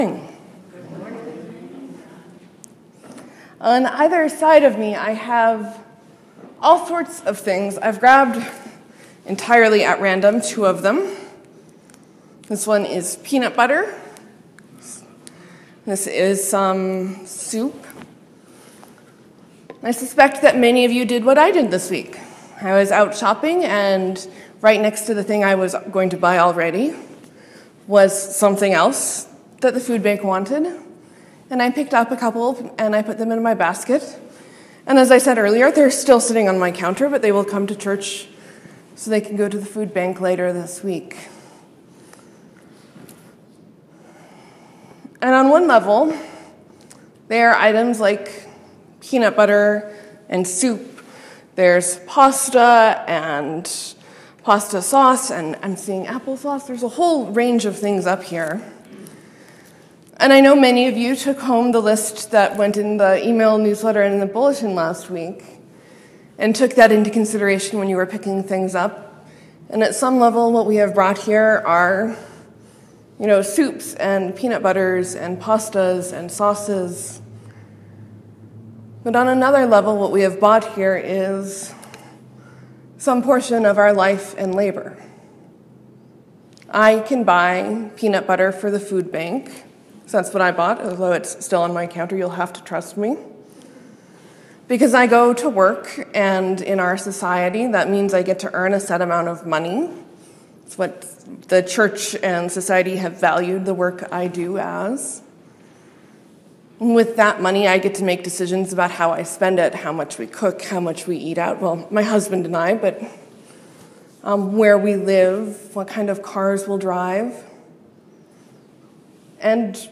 Nota bene: Our sound system was acting oddly so the quality of the recording may have a few odd moments.